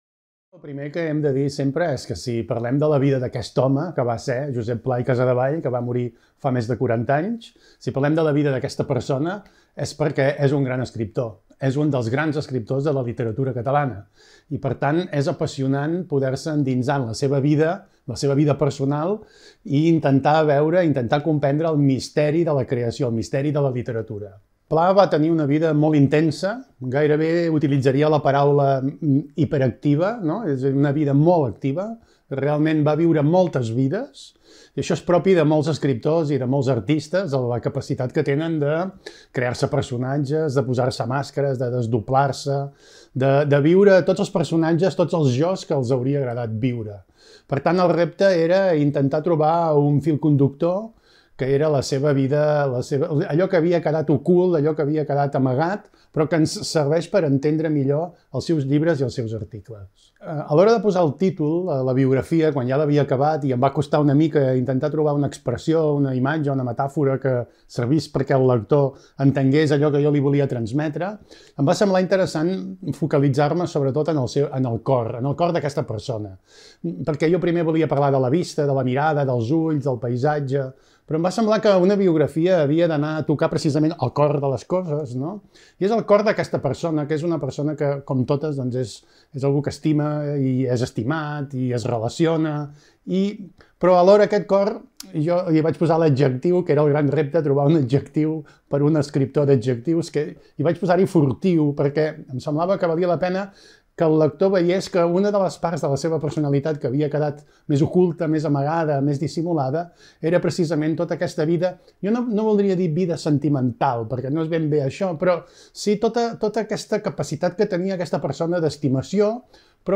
Entrevista televisiva